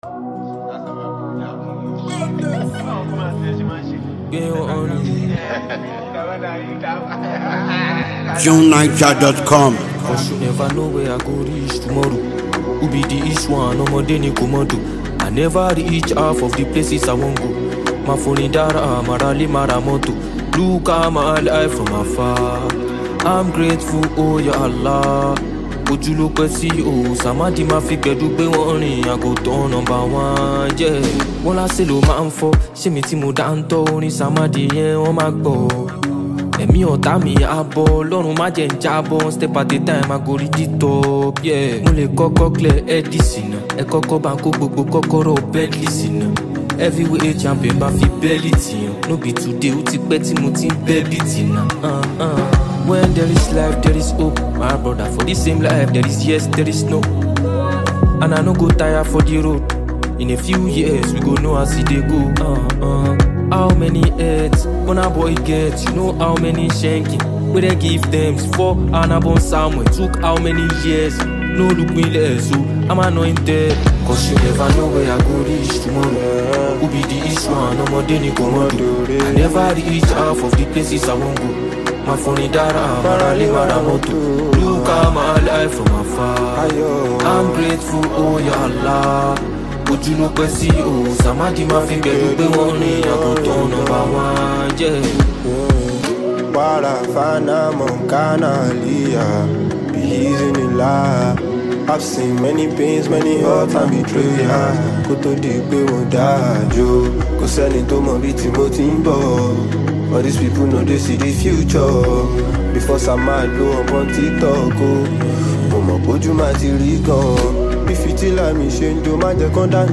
a pop-infused banger and rave-worthy song